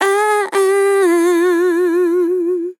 Categories: Vocals Tags: AH, AHHH, DISCO VIBES, dry, english, female, fill, sample
POLI-Vocal-Fills-120bpm-Fm-5.wav